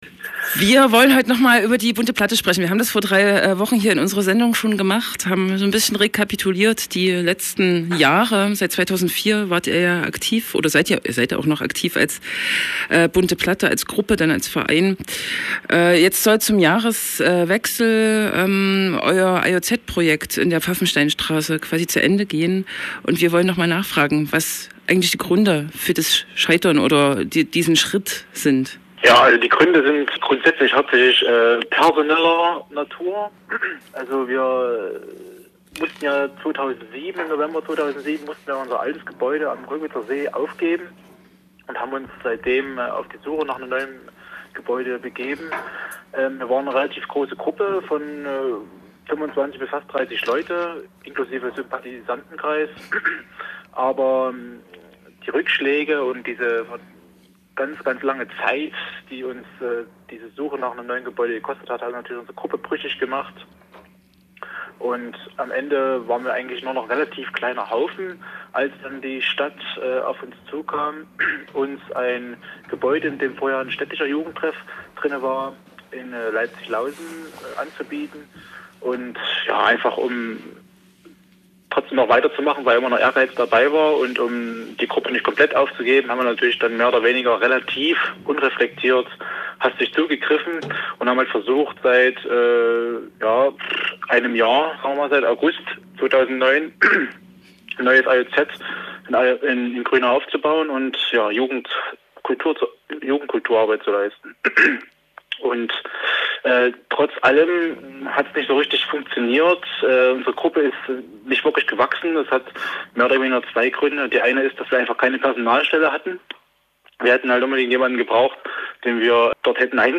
Im Oktober gab der Bunte Platte e.V. offiziell die Schließung des von ihm verwalteten Alternativen Jugendzentrums in Leipzig-Grünau bekannt. Ein Interview zu den Hintergründen und dem wie weiter.